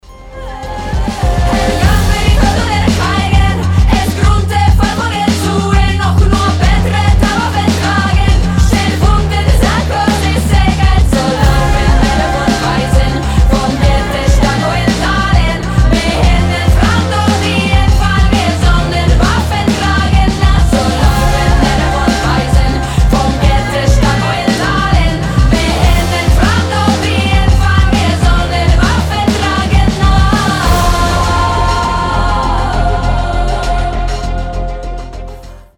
из игр , эпичные